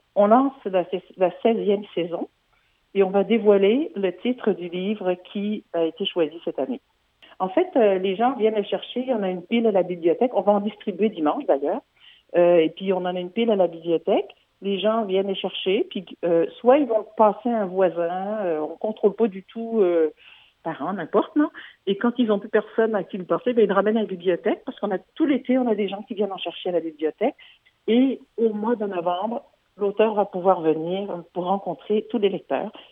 Nathalie Lemaire, mairesse d’Eastman, explique le fonctionnement.
Eastman-Un-livre-un-village_Nathalie-Lemaire-clip.mp3